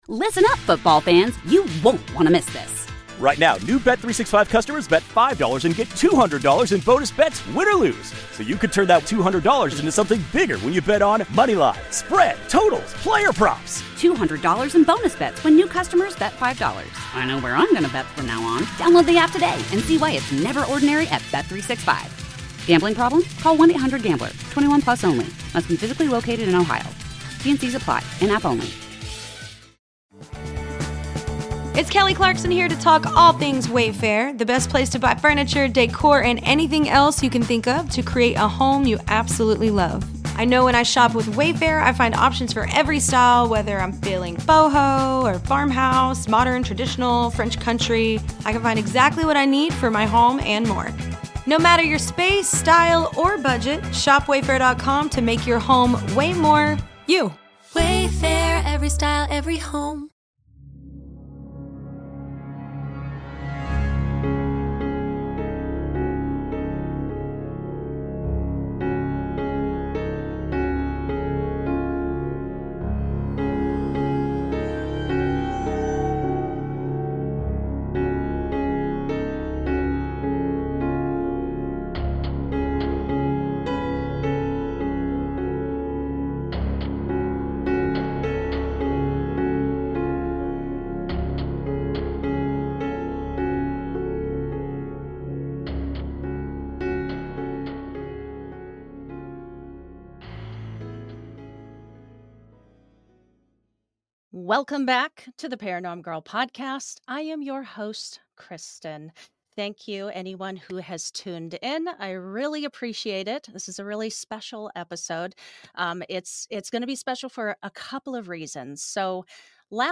The Tale of Moby Wick: The Same Light for Wishmas (LIVE with Paul F Tompkins and Nicole Parker) 1:05:02 Play Pause 9d ago 1:05:02 Play Pause Play later Play later Lists Like Liked 1:05:02 The following was recorded LIVE at the Lodge Room in Los Angeles on December 16th 2025, and is the NINTH annual Off Book Holiday Spectacular!